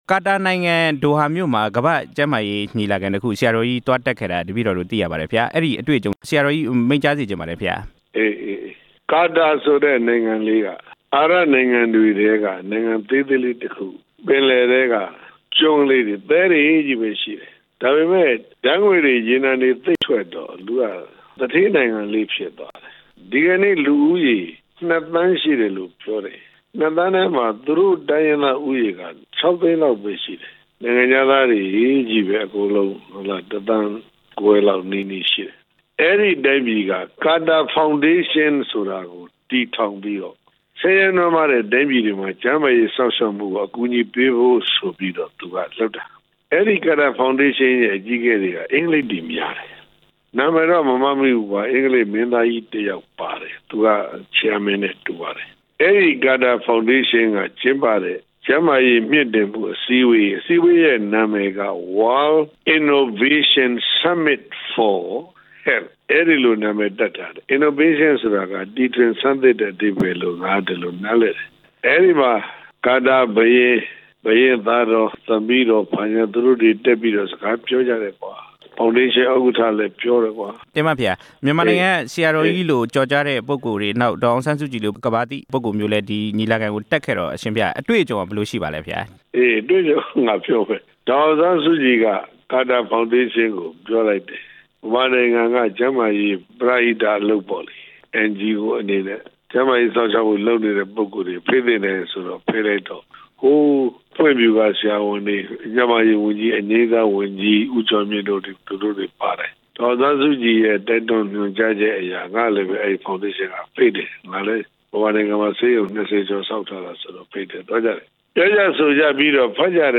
ကျန်းမာရေးကဏ္ဍပြုပြင်ရေး သီတဂူဆရာတော်ကြီးနဲ့ မေးမြန်းချက်